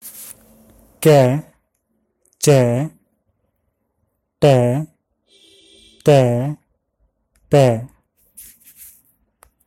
gh, jh, ḍh, dh, bh의 몇 가지 펀자브어 특유의 음높이